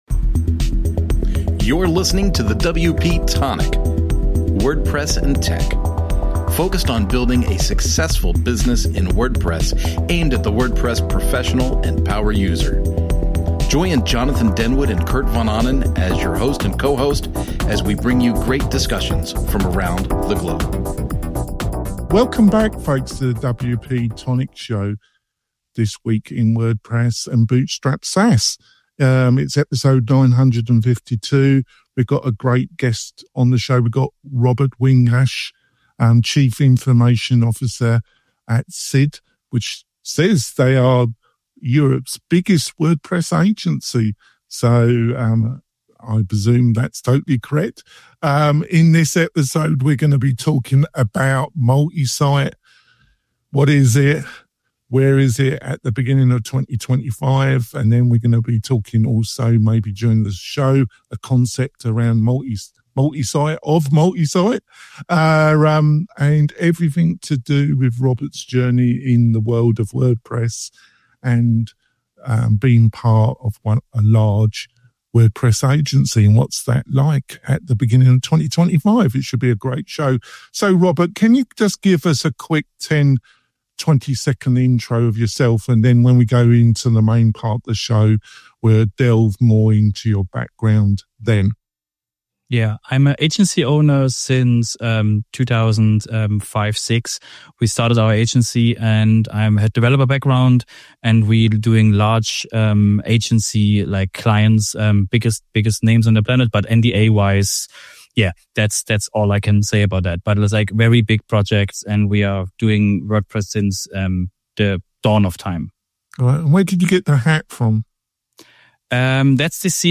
We interview some creative WordPress and startup entrepreneurs plus online experts who, with their insights, can help you build your online business.